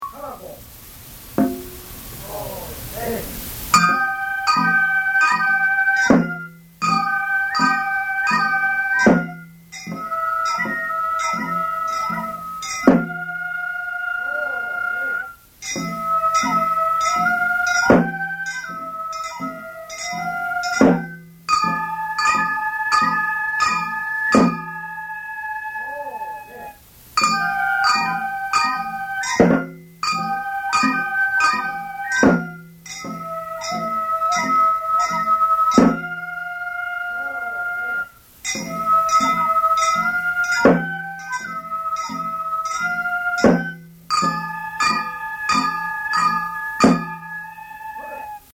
唐子 天孫神社の鳥居の前で所望（しょもう）を奉納した後に演奏する曲で、２回繰り返す。
昭和62年11月1日　京都太秦　井進録音スタジオ